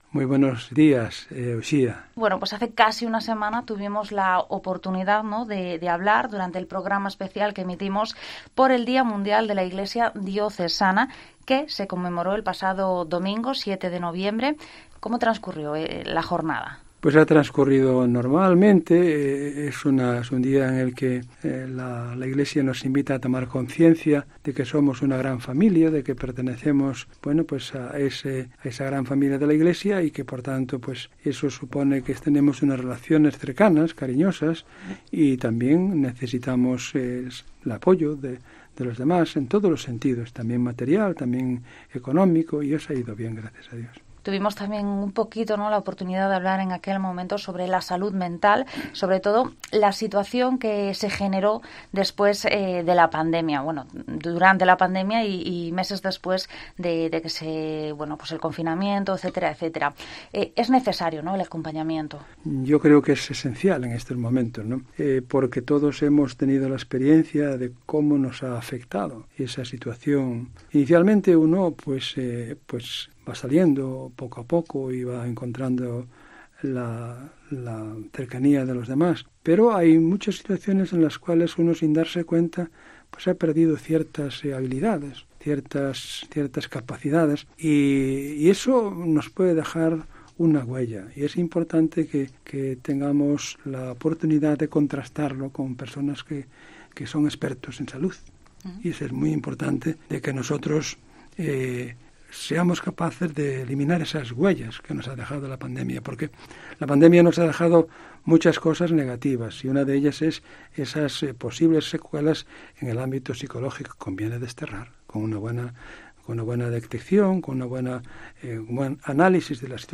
Profundizamos en la primera fase del Sínodo 2021 - 2023 con el Obispo Monseñor Don Luis Quinteiro Fiuza
Entrevista